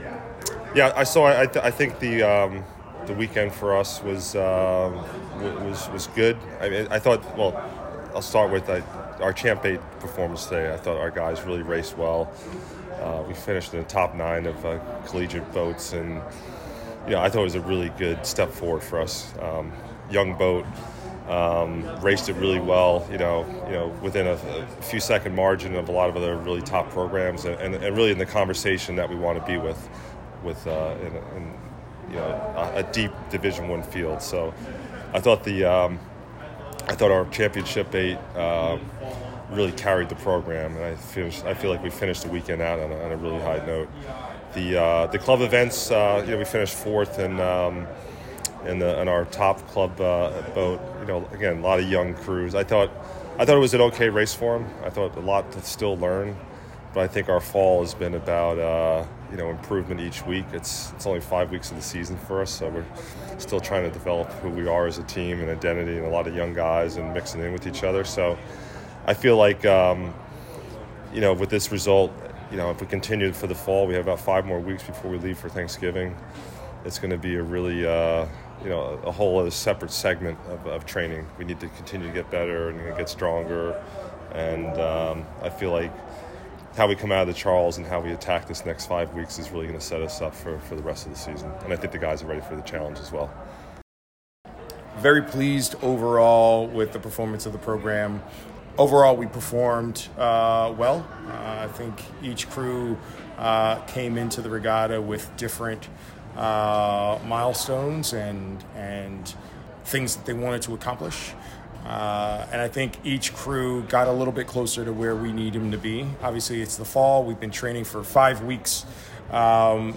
HOCR_Coaches_Comments.mp3